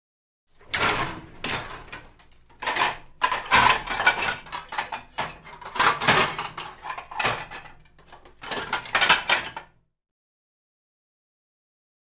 Dishwasher; Dishes Being Loaded; Dishes Clanking Against One Another. Close Perspective. Kitchen, Restaurant.